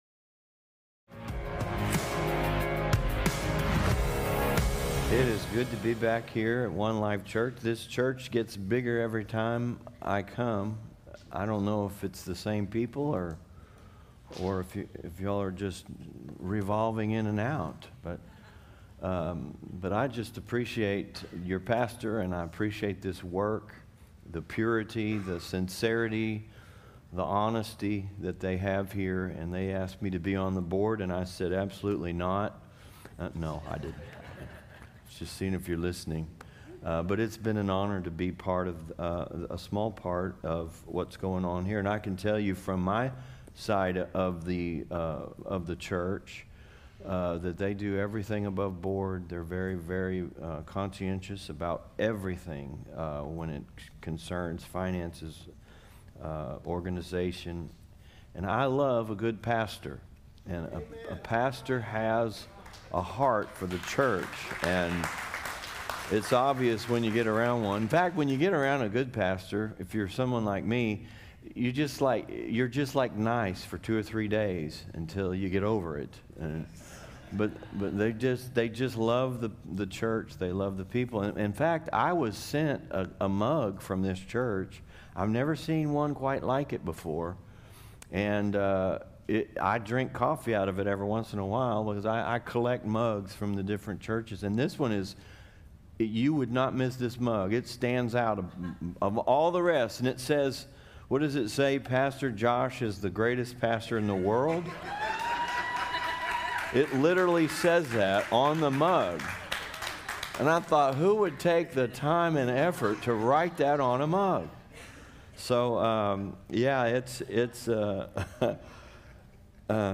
1 Guest Speaker